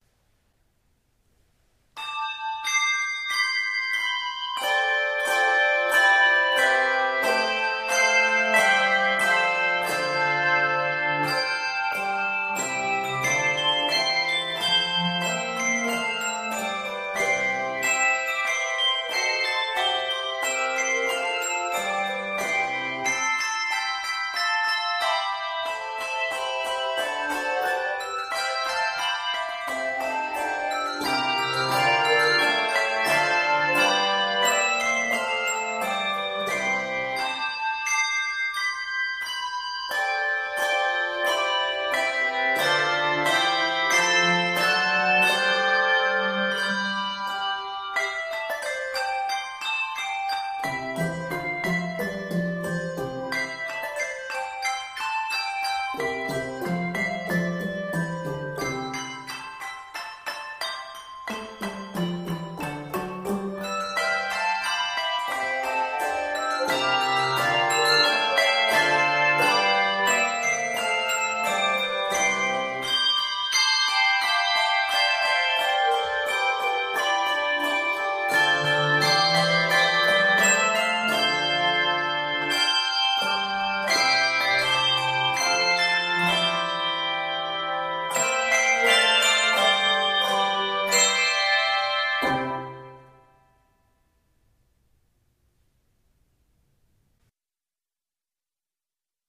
is given an atmospheric and truly unique setting